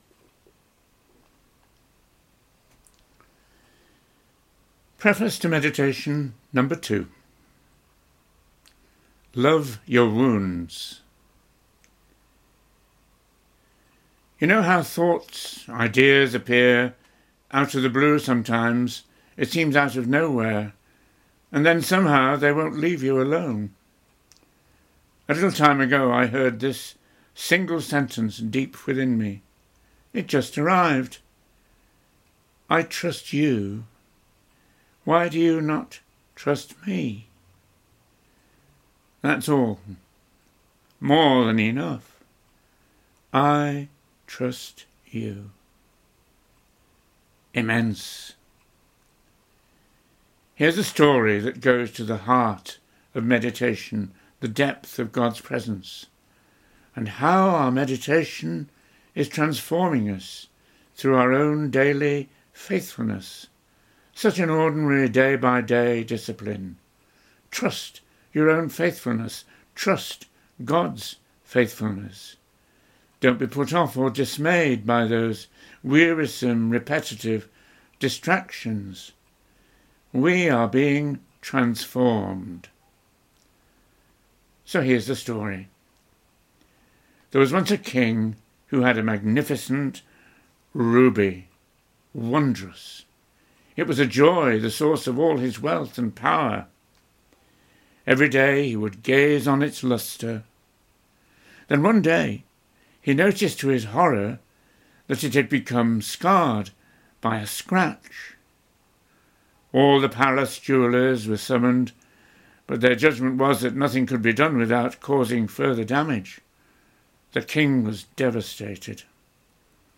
Recorded Talks